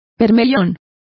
Complete with pronunciation of the translation of vermilions.